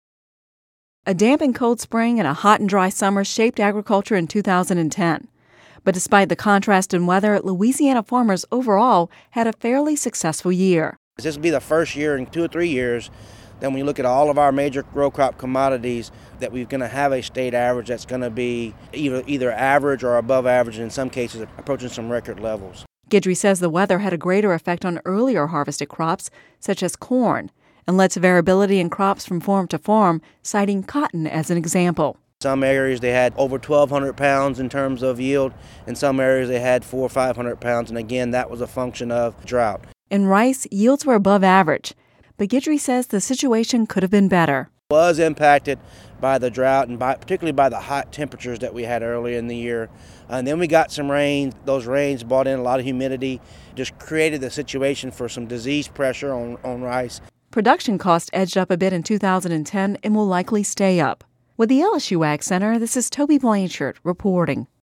(Radio News 01/10/11) A damp and cold spring and hot and dry summer shaped agriculture in 2010. Despite the contrast in weather, Louisiana farmers had a fairly successful year overall.